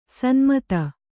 ♪ sanmata